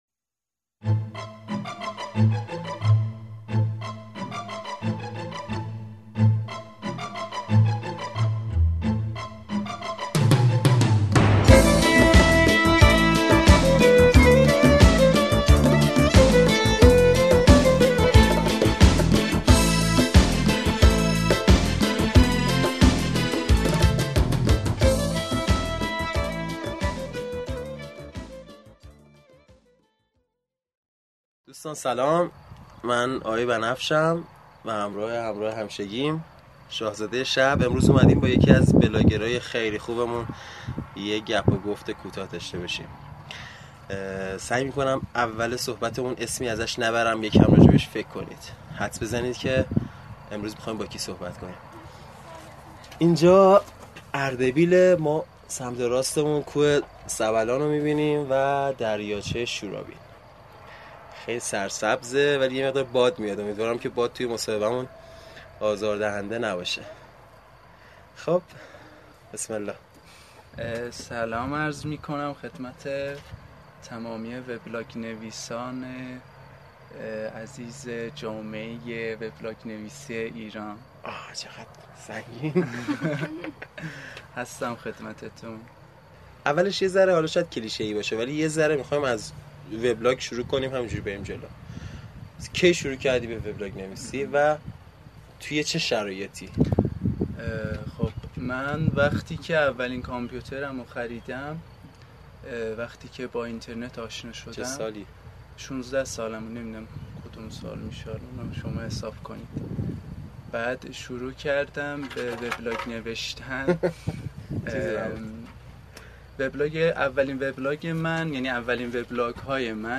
mosahebe-bablogerha8-64.mp3